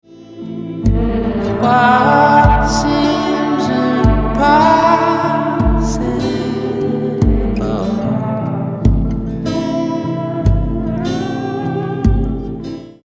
Distorted Strings/Horns Later Resolve as Vocals